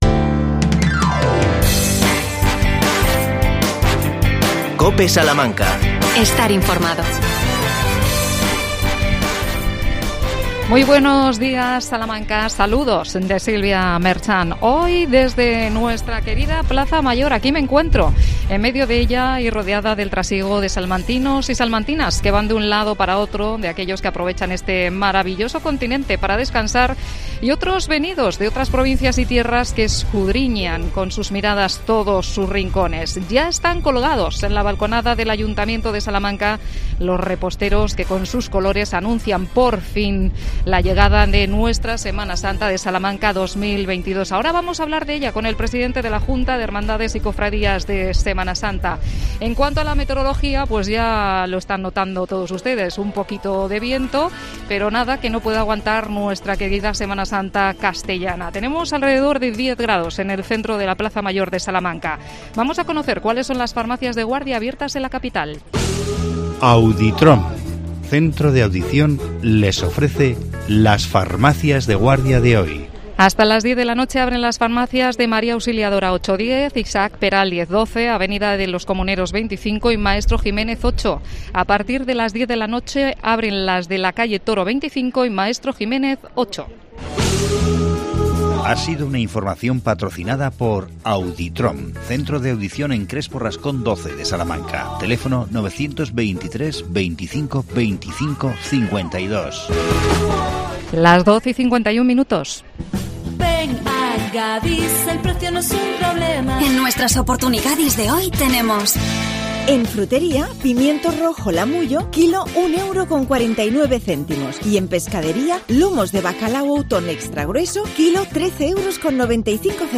Entrevista, desde la Plaza Mayor de Salamanca